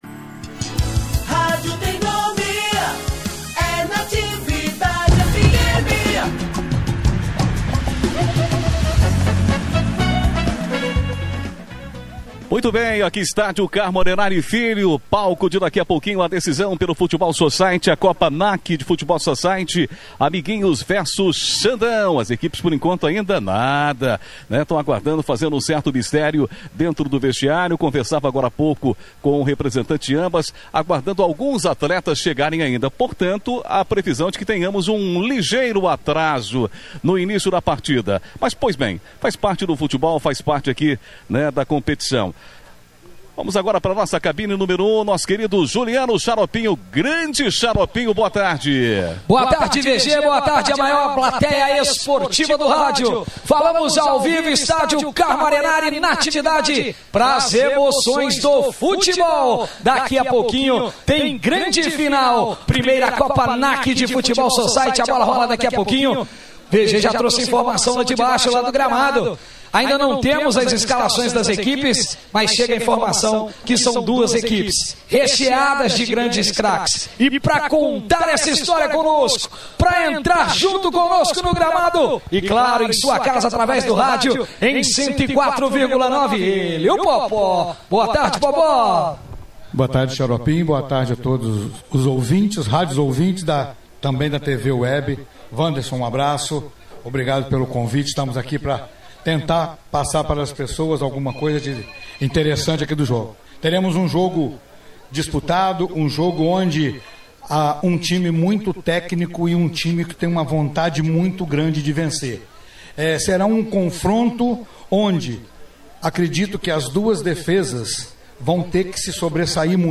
A Rádio Natividade transmitiu neste último domingo (30), em parceria com a Web TV Porciúncula, direto do Estádio Carmo Arenari, a grande final da Primeira Copa NAC de Futebol Society.